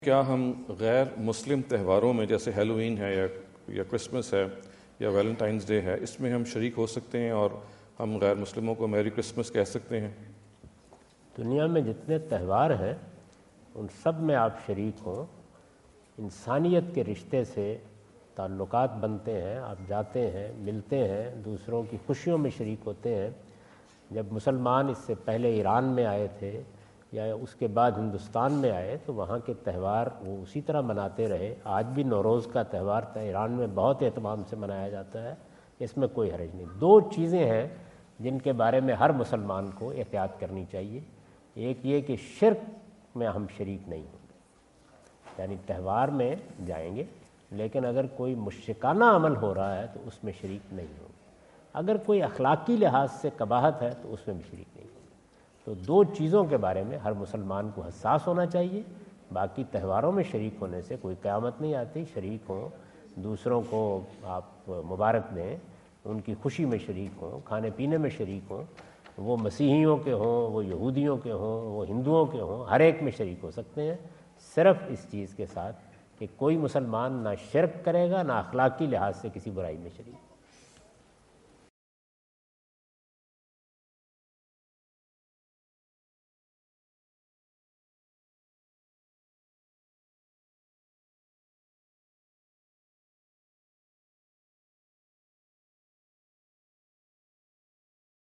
Javed Ahmad Ghamidi answer the question about "Etiquette for Muslims to Attend Non-Muslim Festivals" asked at The University of Houston, Houston Texas on November 05,2017.